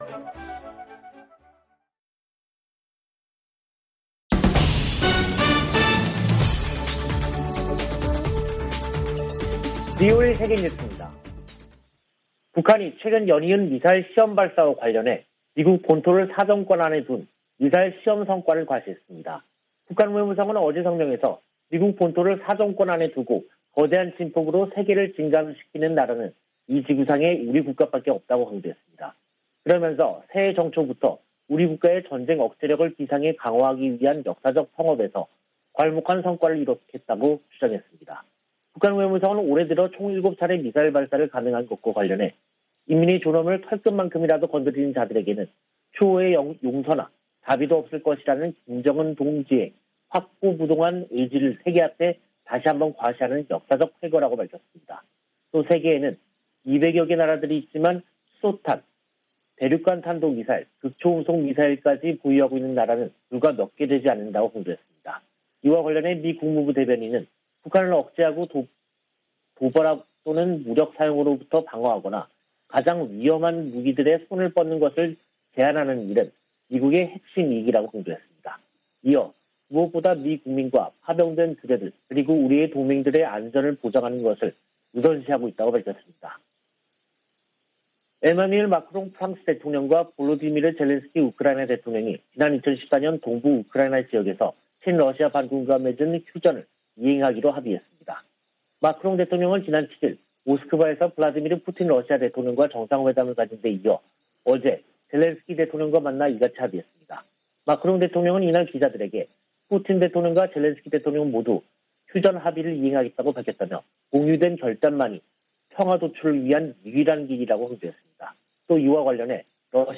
VOA 한국어 간판 뉴스 프로그램 '뉴스 투데이', 2022년 2월 9일 2부 방송입니다. 미 국무부는 대북 인도주의 지원을 위한 '제재 면제' 체제가 가동 중이라며, 제재가 민생을 어렵게 한다는 중국 주장을 반박했습니다.